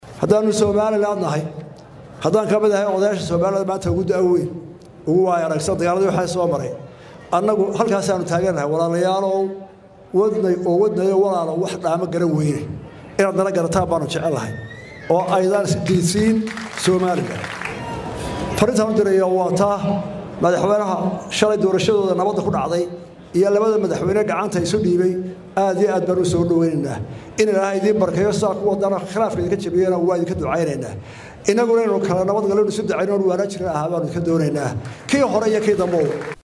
Madaxweynaha Somaliland Muuse Biixi Cabdi ayaa mar kale hambalyeeyay madaxweynaha la doortay ee Soomaaliya Xasan Sheekh Maxamuud. Muuse Biixi oo hadal ka jeediyay munaasabad lagu xusayay 31 sano guuradii kasoo wareegtay markii Somaliland sheegtay inay ka go’day Soomaaliya inteeda kale ayaa ugu baaqay Soomaaliya in ay ixtiraamto qaddiyada madax-bannaanida Somaliland.